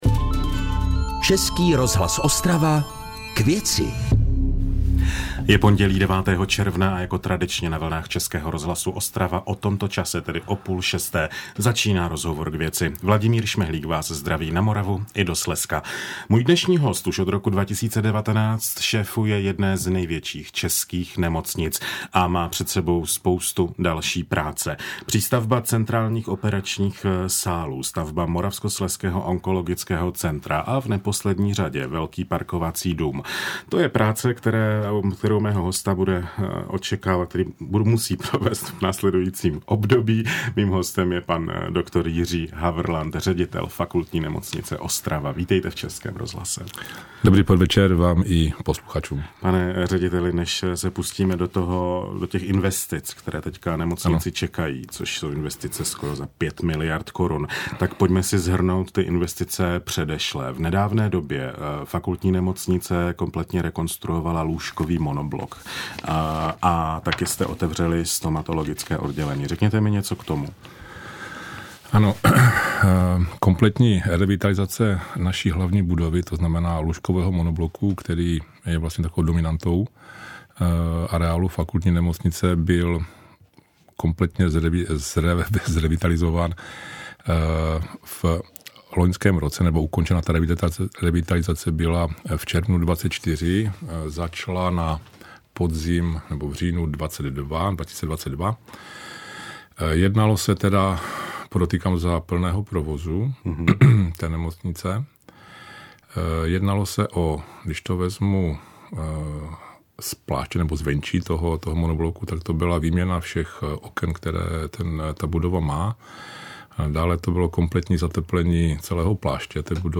Zprávy ČRo Ostrava